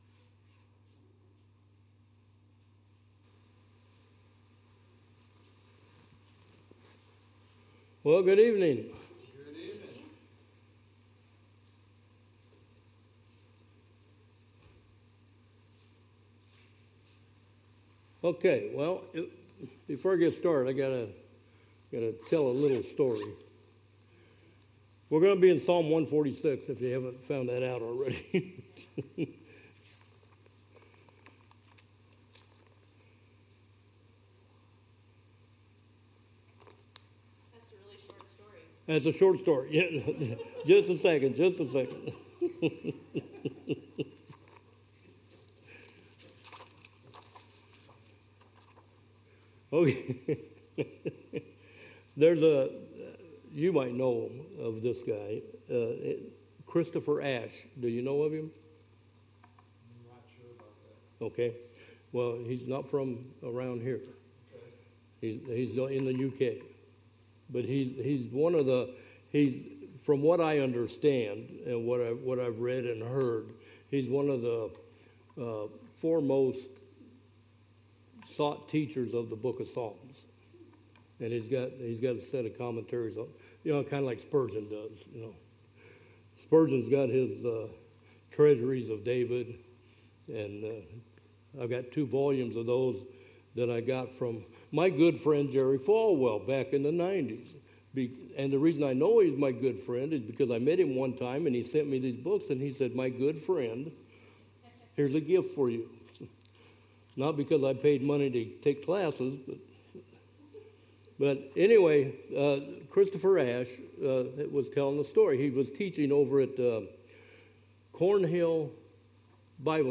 Sermon Series Psalms | Southview Bible Church